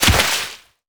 water_spell_impact_hit_04.wav